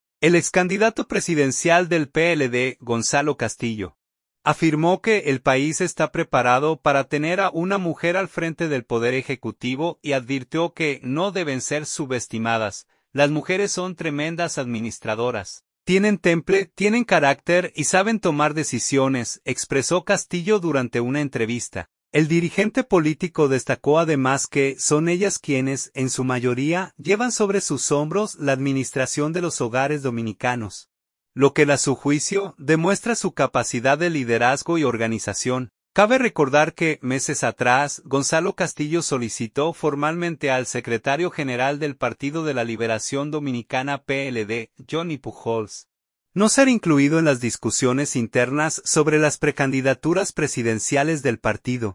“Las mujeres son tremendas administradoras. Tienen temple, tienen carácter y saben tomar decisiones”, expresó Castillo durante una entrevista.